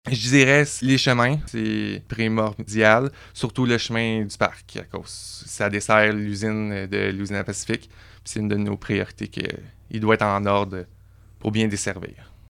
Le maire de Bois-Franc, Samuel Malette, précise que l’entretien de ces voies stratégiques pour l’économie locale est une priorité du conseil municipal :